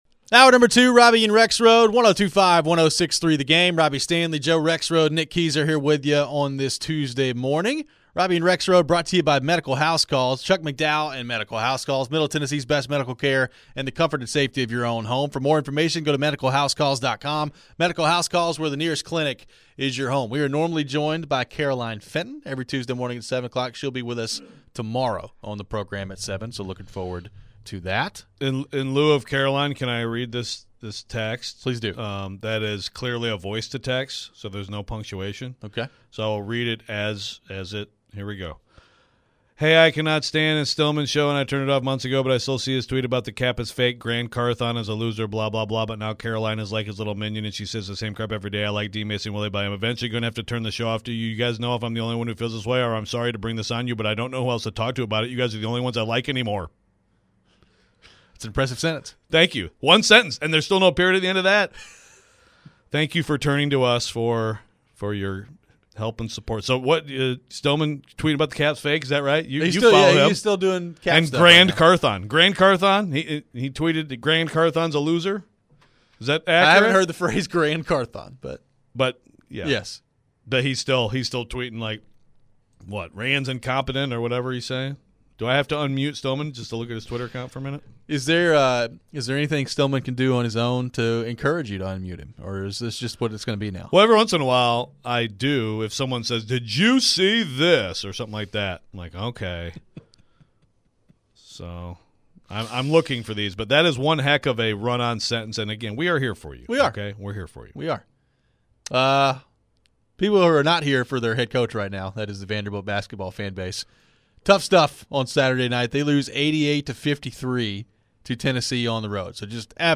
discussed & took calls on Vandy's season.